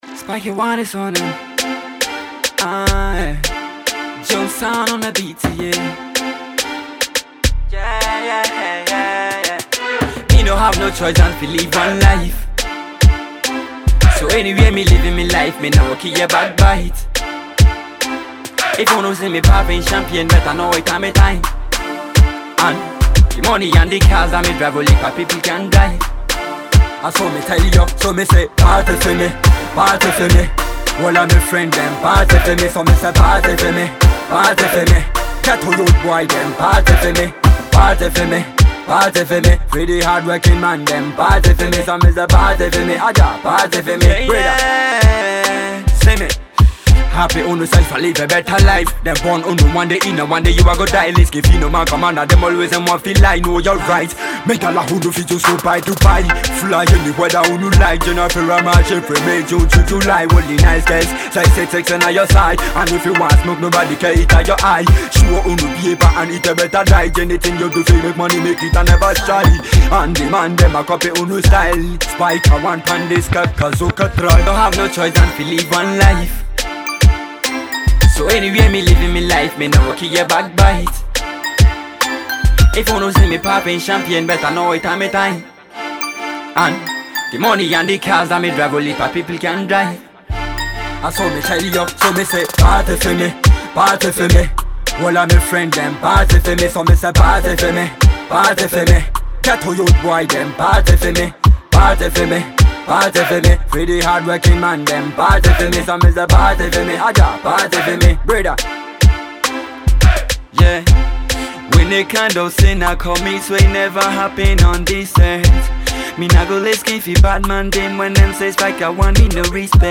Reggae/Dancehall
dancehall tune